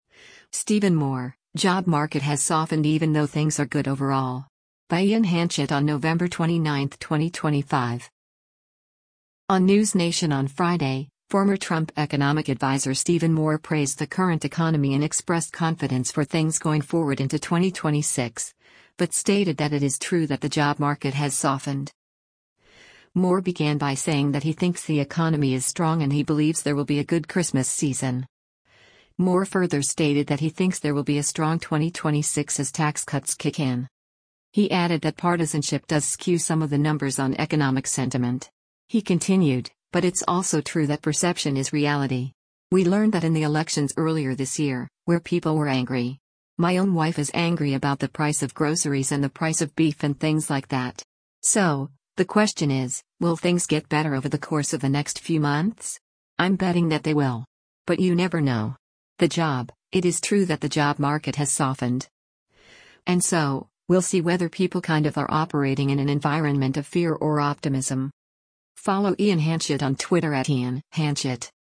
On NewsNation on Friday, former Trump Economic Adviser Stephen Moore praised the current economy and expressed confidence for things going forward into 2026, but stated that “it is true that the job market has softened.”